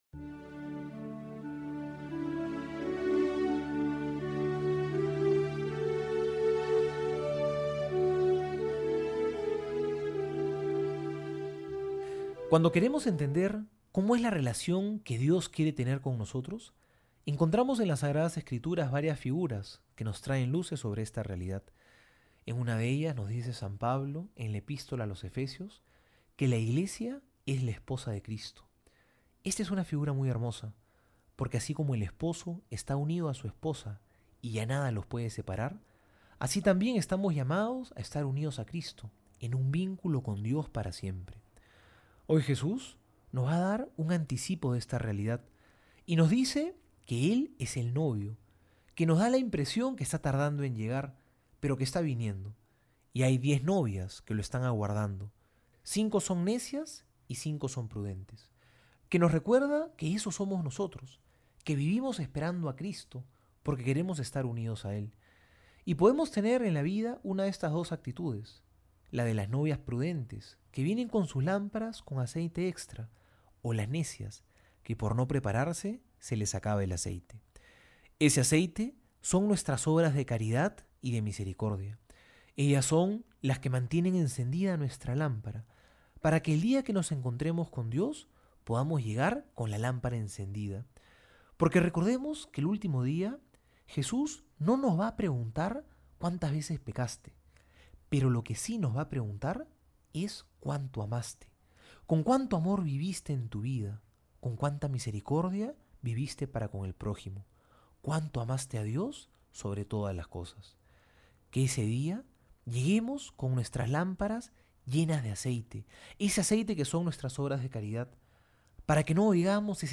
Homilía para hoy:
Viernes homilia San Mateo 25 1-13.mp3